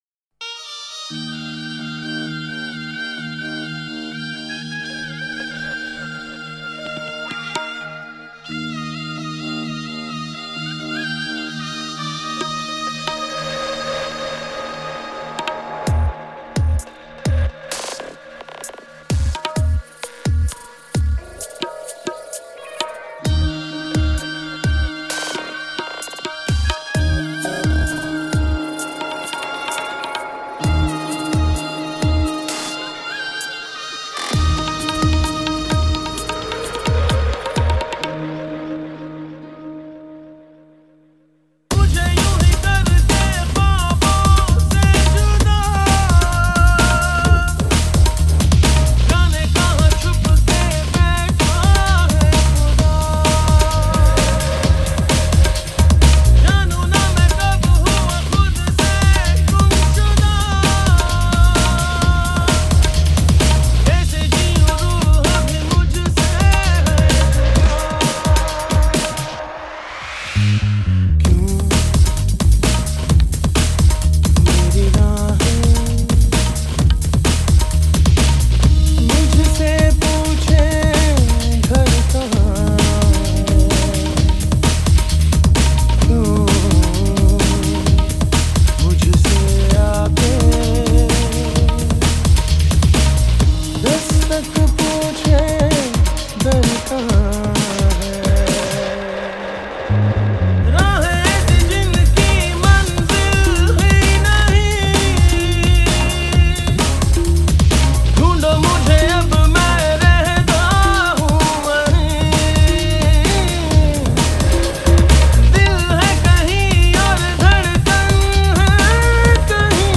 Bollywood MP3 Songs